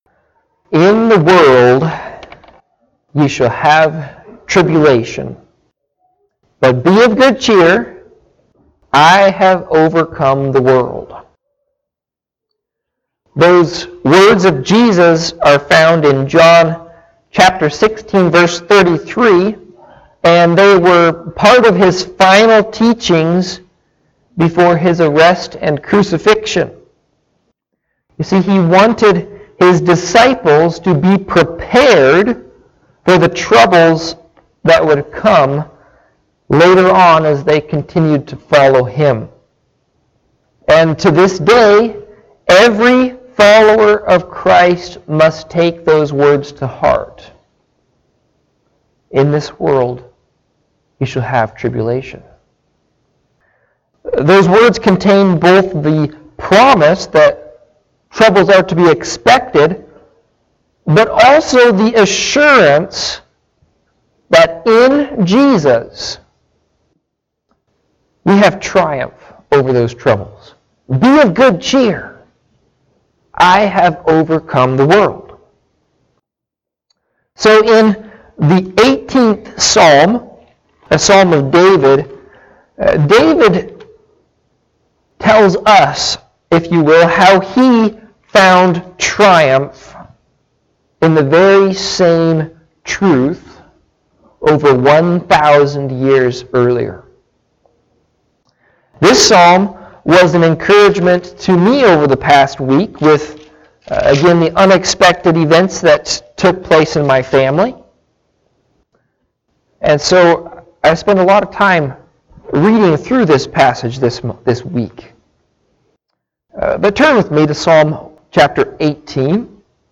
Listen to Audio of the sermon or Click Facebook live link above.
Service Type: Morning Sevice